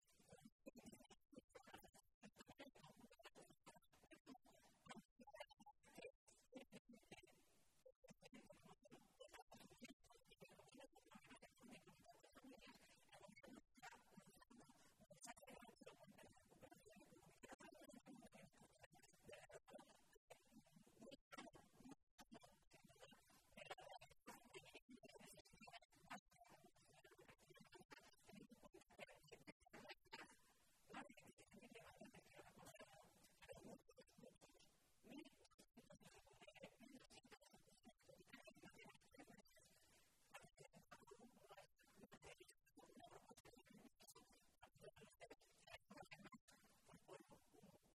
Maestre se pronunciaba de esta manera esta mañana, en Toledo, en una comparecencia ante los medios de comunicación en la que detallaba que, hasta el pasado 30 de marzo, “han sido 1.204 los expedientes de ejecución hipotecaria iniciados en nuestra tierra, diez puntos por encima de la media nacional”, y llamaba la atención sobre este “repunte tan importante precisamente durante el primer trimestre de este año, el 2014, el año en el que los discursos y la propaganda de los Gobiernos de Rajoy y Cospedal nos dicen que es el año de la recuperación”.
Cortes de audio de la rueda de prensa